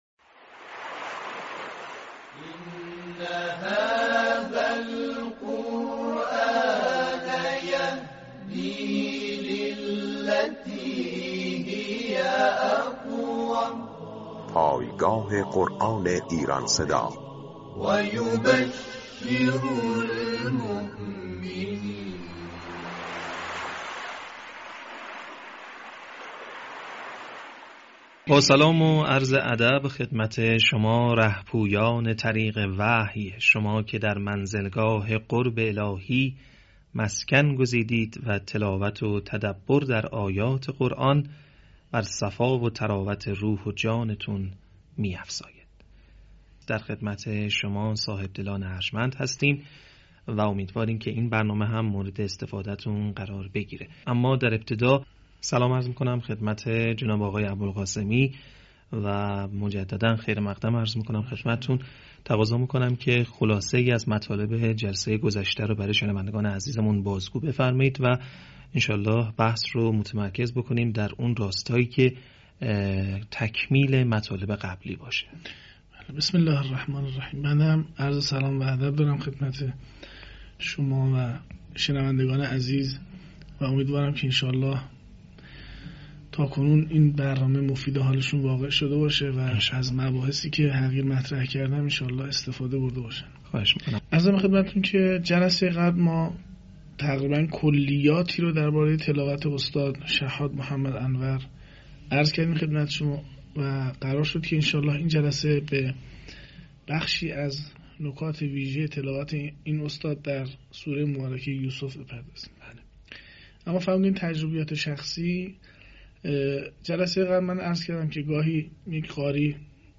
صوت | تحلیل تلاوت «شحات» در مقام بیات و ماهور